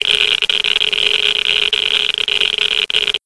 geiger_level_3.ogg